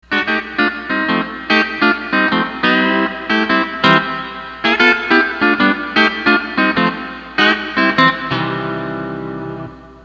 Lots of 9th chords and C6 type sounds are available using the split on string 5.